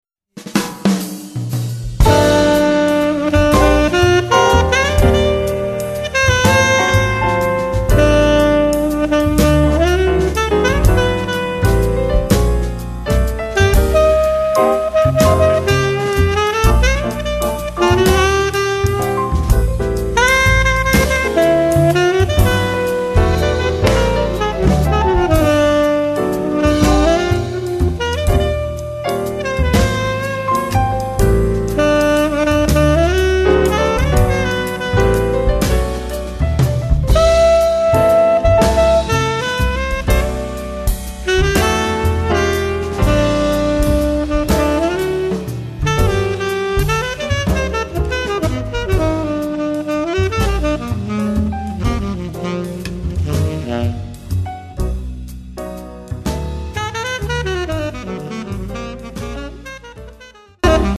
sax tenore
piano
contrabbasso
batteria